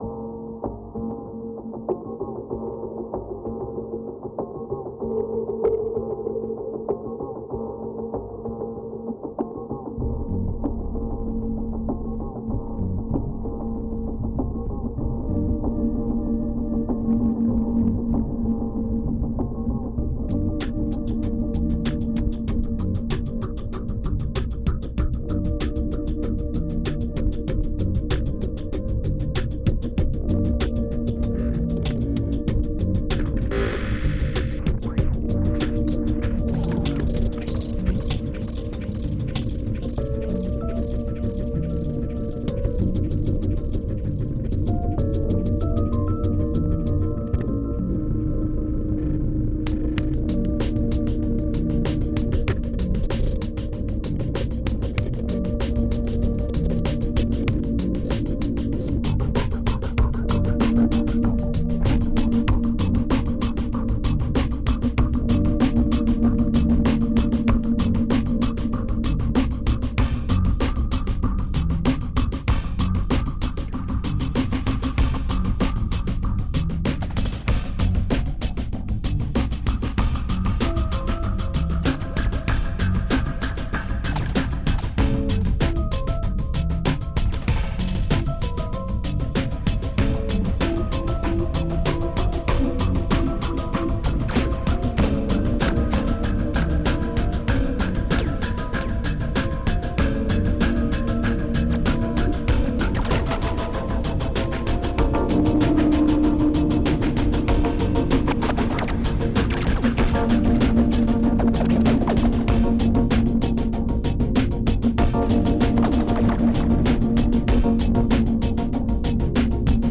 2111📈 - -40%🤔 - 96BPM🔊 - 2012-05-12📅 - -413🌟